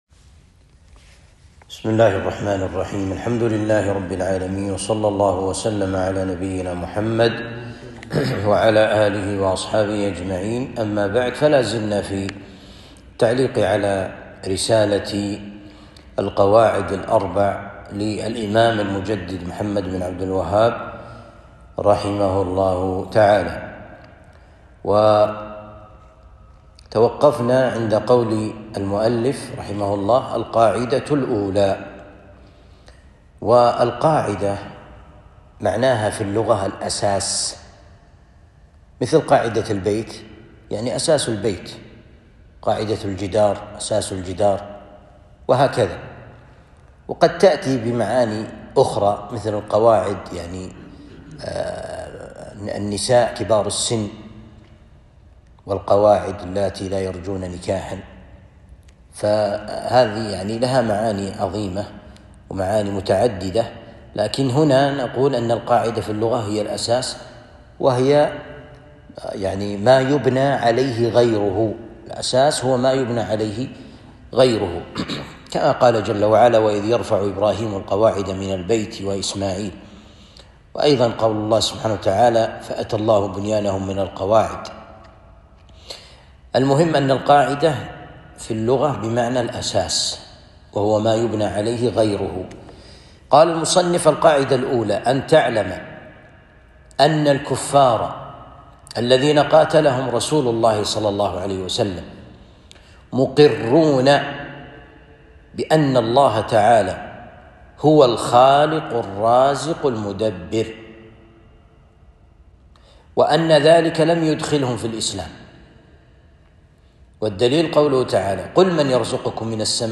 الدرس الثالث من كتاب القواعد الأربع